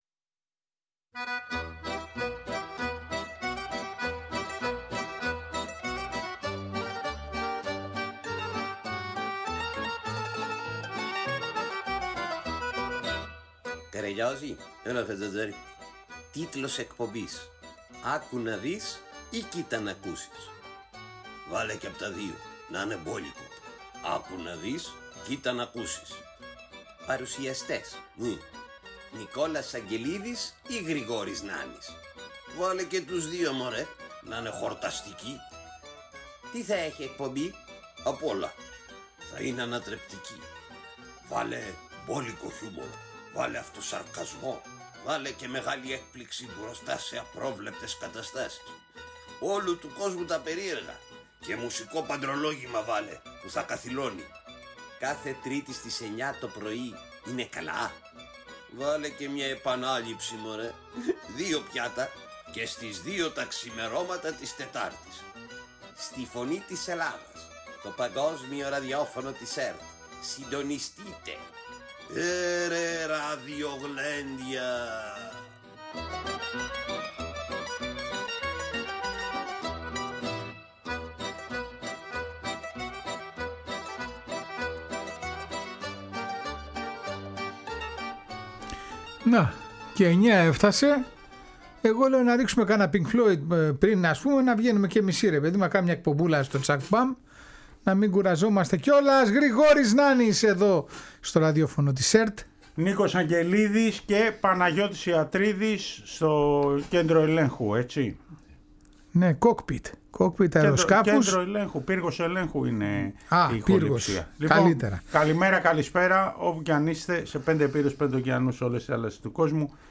Τέλος μαθαίνουμε την ιστορία και ακούμε τον ύμνο του Αστέρα Παστίδας στη Ρόδο…